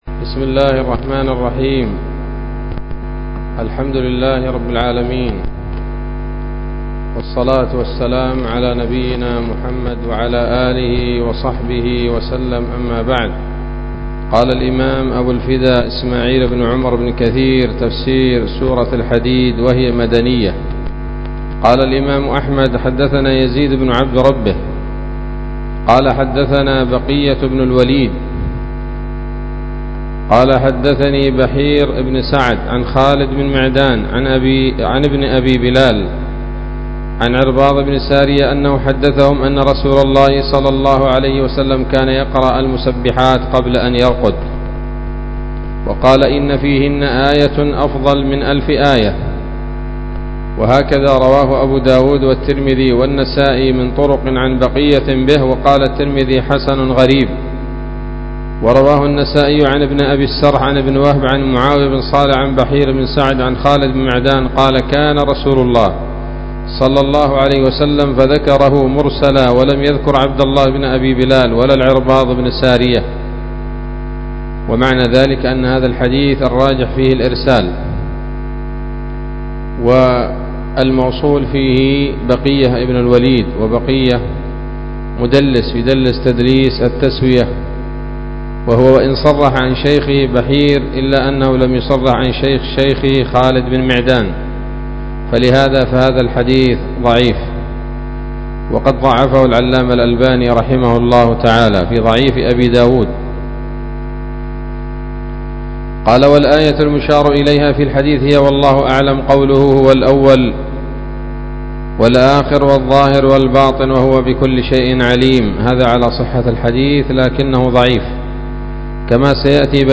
الدرس الأول من سورة الحديد من تفسير ابن كثير رحمه الله تعالى